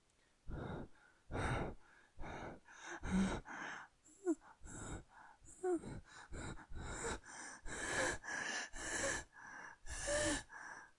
描述：免费声音，效果efecto sonoro producido por la garganta